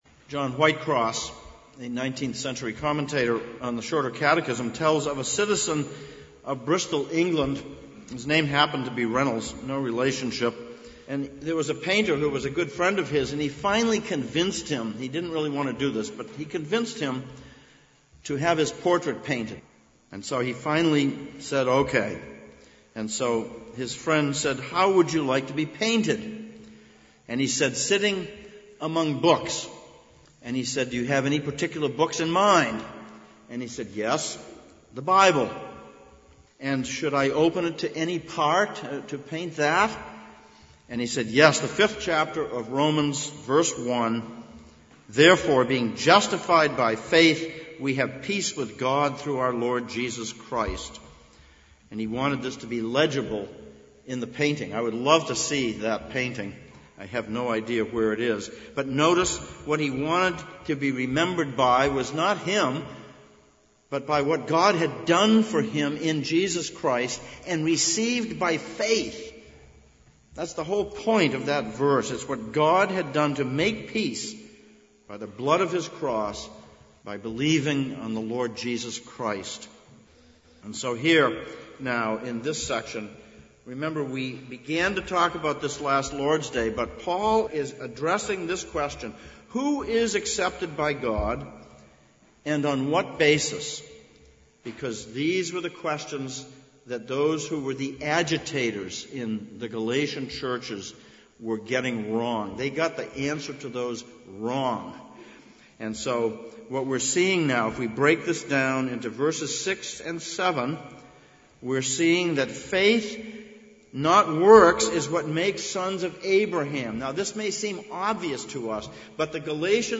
Genesis 15:1-20 Service Type: Sunday Morning Sermon text